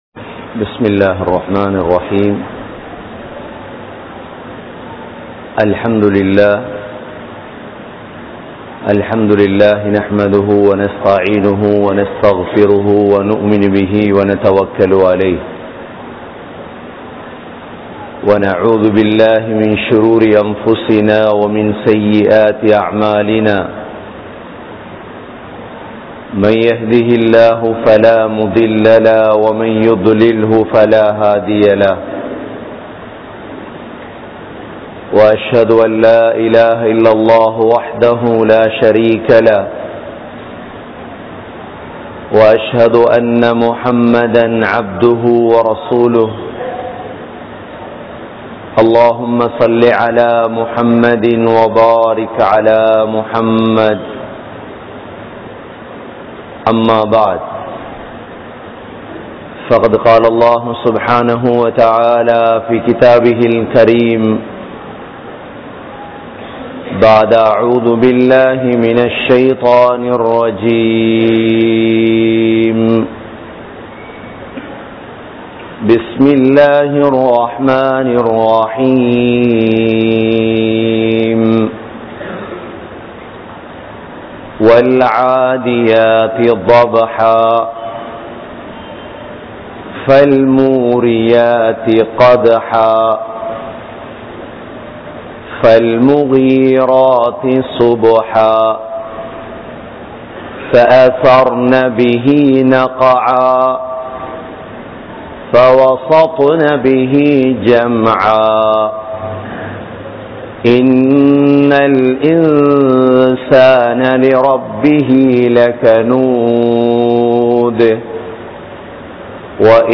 Nantri ketta Manitharhal(நன்றி கெட்ட மனிதர்கள்) | Audio Bayans | All Ceylon Muslim Youth Community | Addalaichenai
Aluthgama, Dharga Town, Meera Masjith(Therupalli)